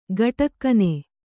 ♪ gaṭakkane